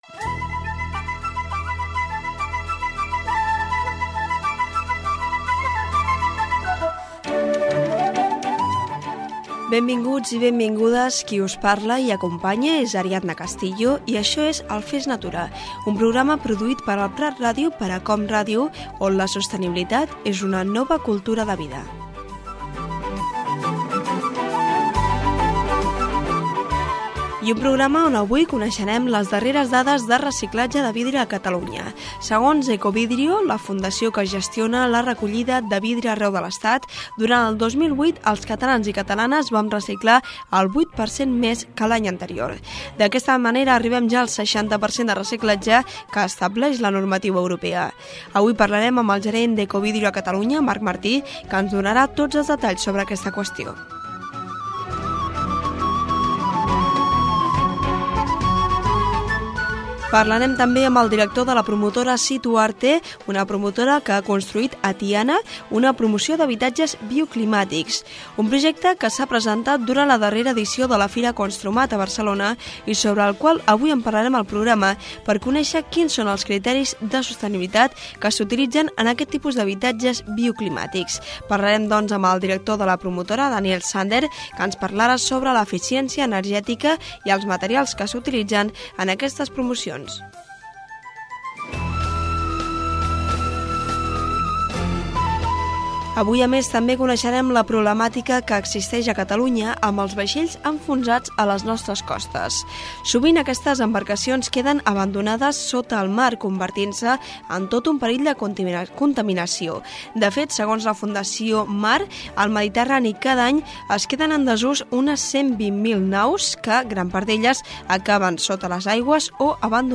Benvinguda al programa, amb identificació i sumari del programa sobre sostenibilitat com a nova cultura de vida.
Divulgació